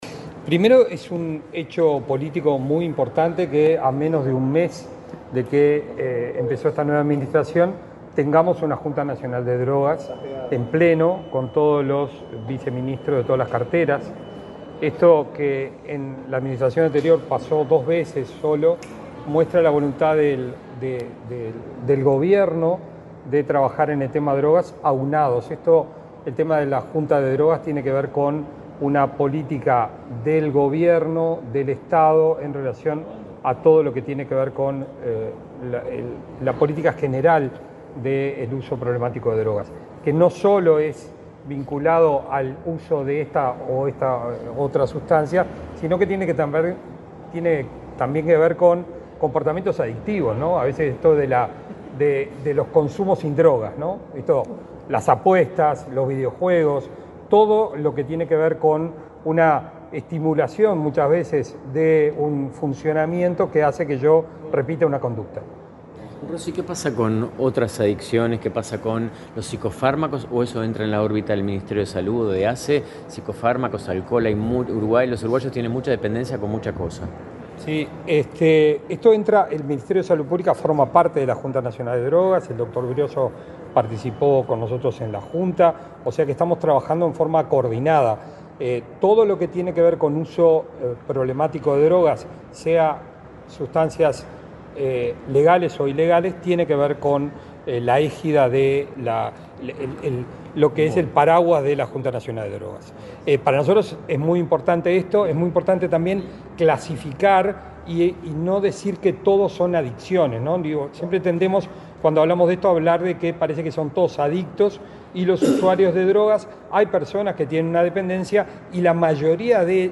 Declaraciones del secretario general de la Junta Nacional de Drogas, Gabriel Rossi
El secretario general de la Junta Nacional de Drogas, Gabriel Rossi, dialogó con la prensa, en la Torre Ejecutiva, luego de participar de la primera